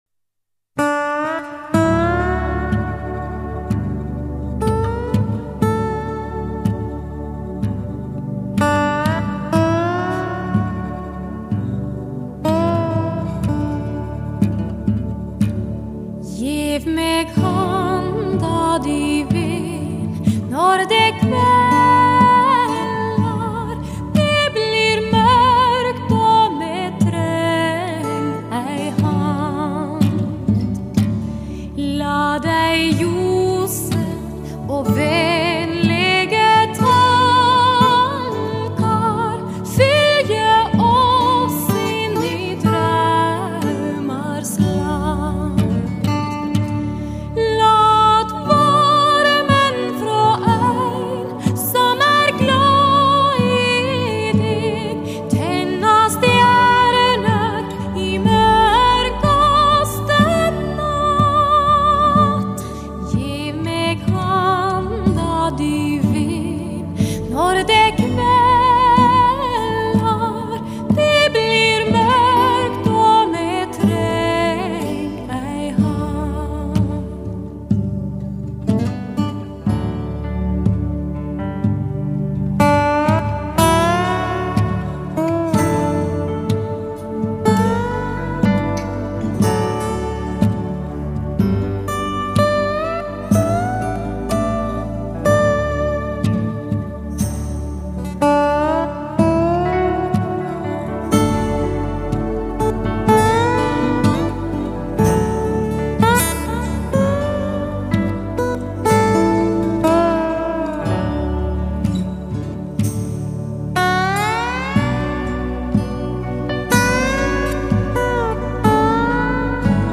极致锻造 跨越国界女声典范 吟唱灵魂的冷调激越 。